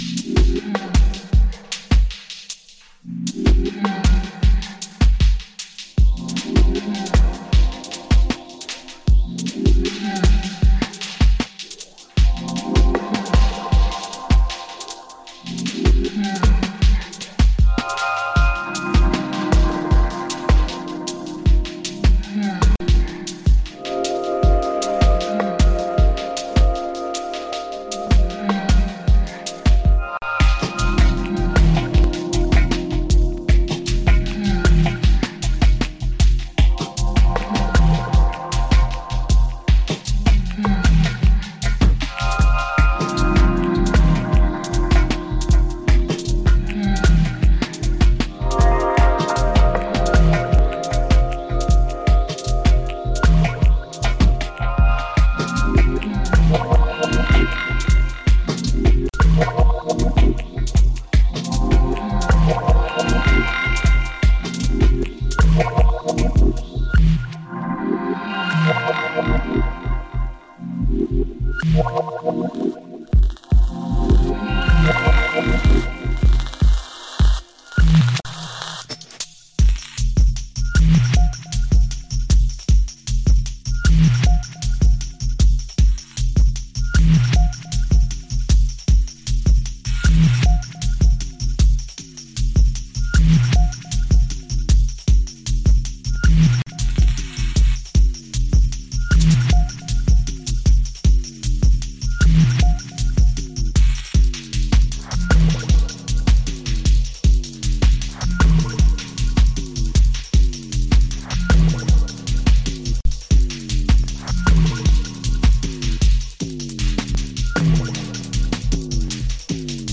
ダブステップとフットワークが融合、そのいずれでもない新型感が漂うハーフタイム重低音ローラー155BPMチューン